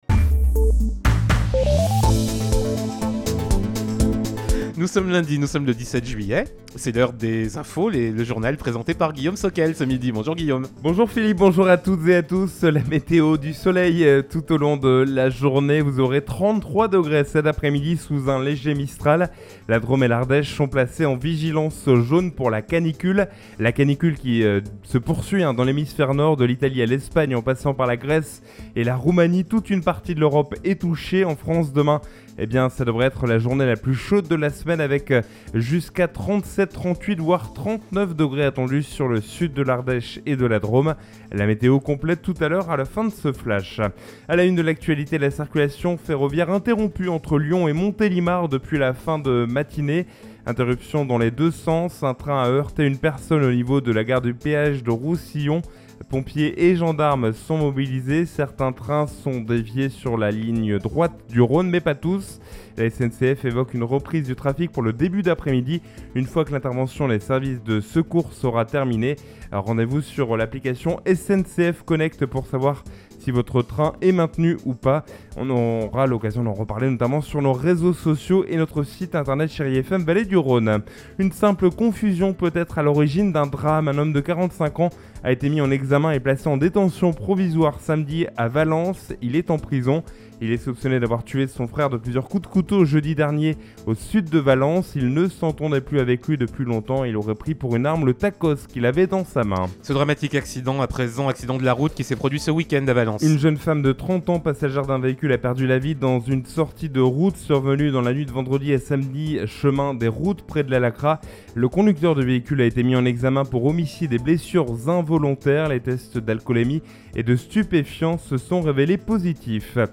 in Journal du Jour - Flash